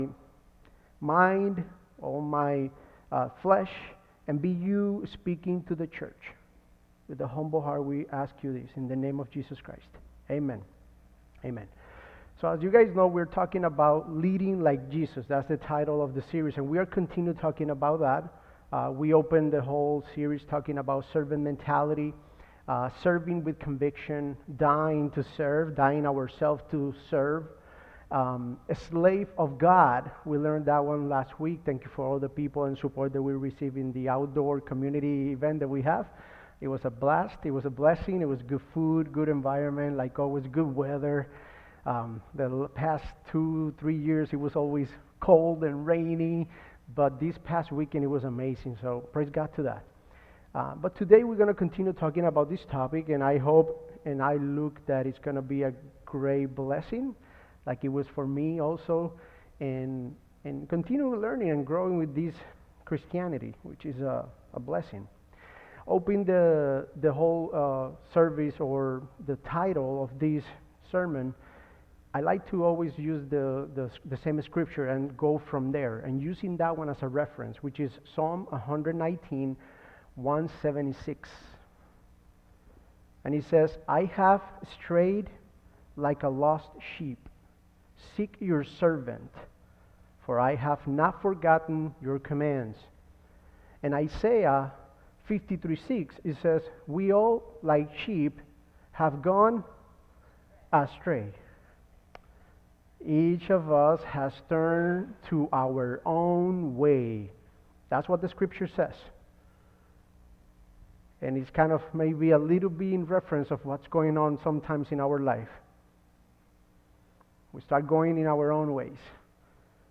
Sermons by FLC Elburn